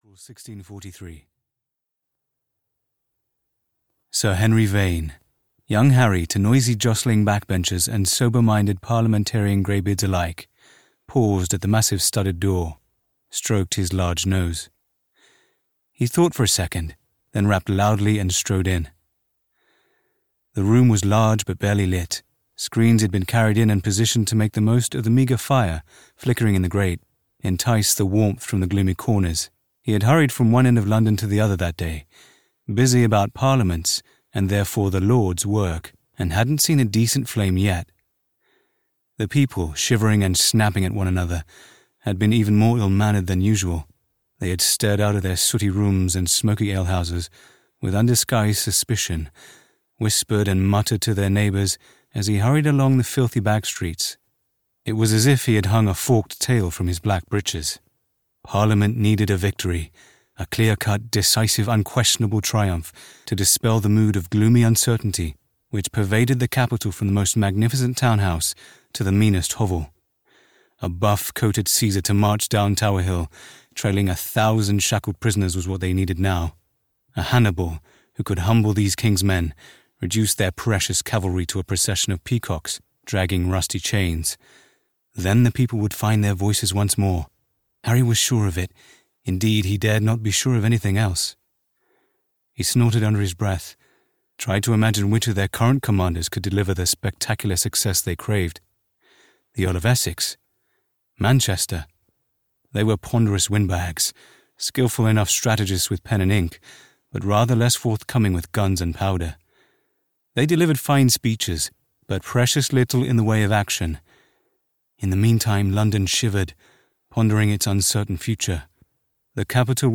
Turncoat's Drum (EN) audiokniha
Ukázka z knihy